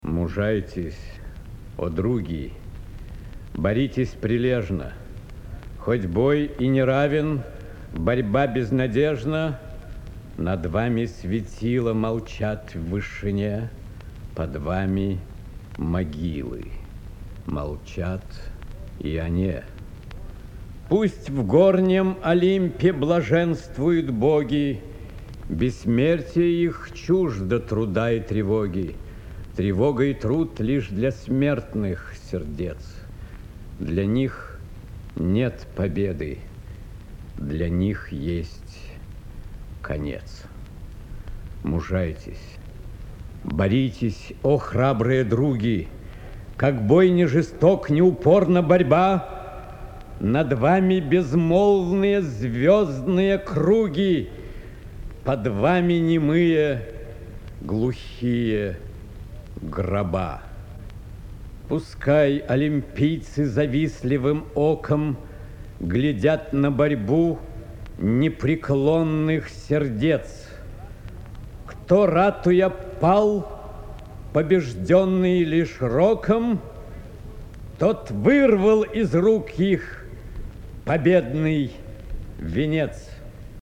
2. «Ф. Тютчев – Два голоса (читает И. Смоктуновский)» /